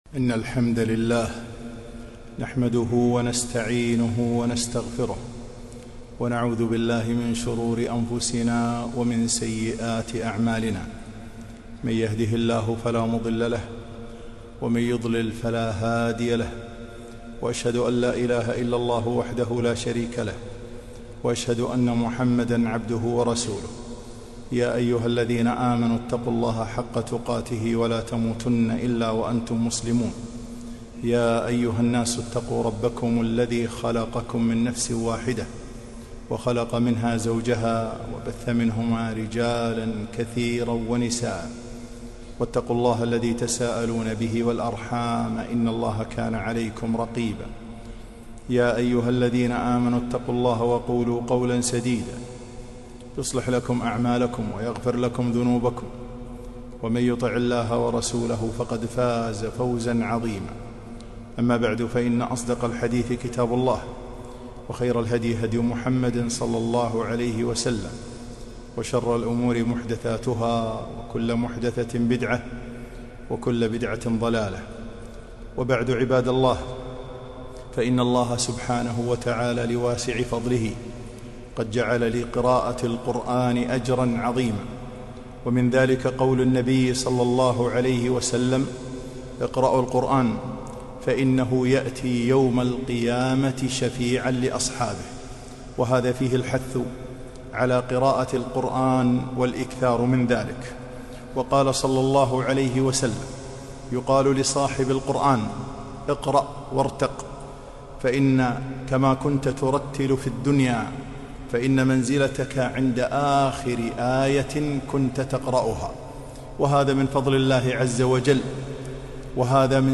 خطبة - من فضائل سور القرآن - دروس الكويت